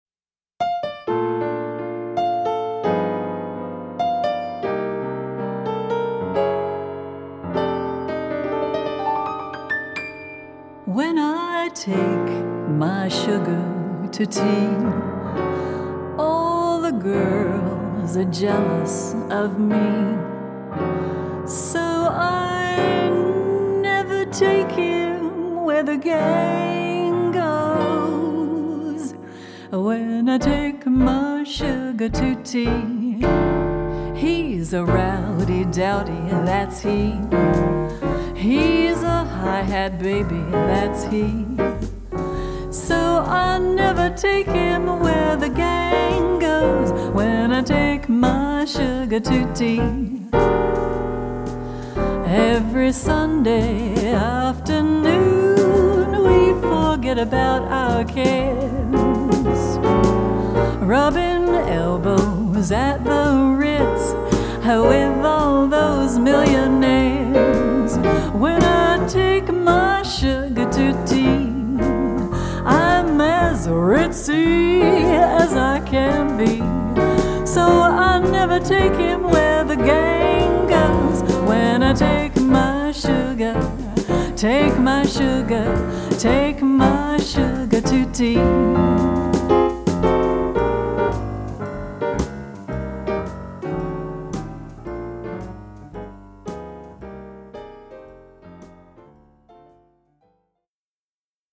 Suite 16 Recording Studio